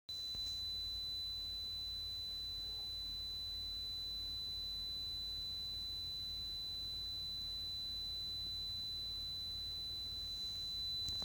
聴力検査サンプル音(SamplefortheHearingTest)
高音域（MP3：176KB）(High-pitched)
4000hz.mp3